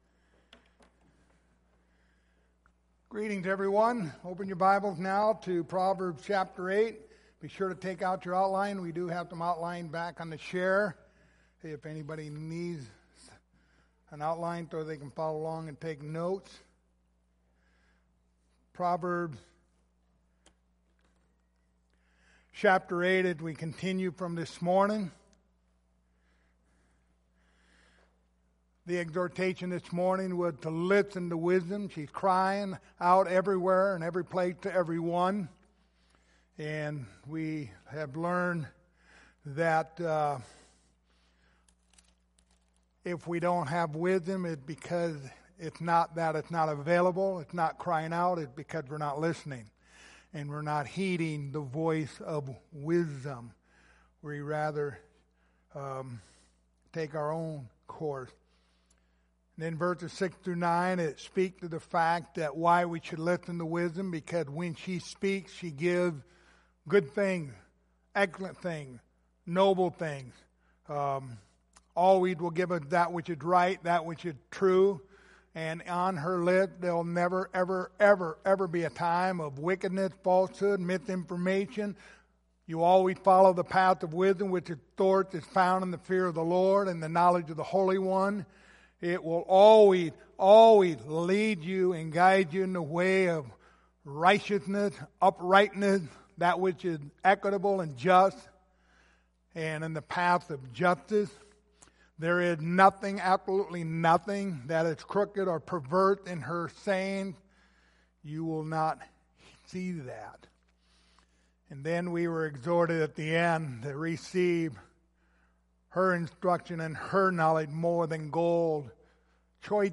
The Book of Proverbs Passage: Proverbs 8:12-21 Service Type: Sunday Evening Topics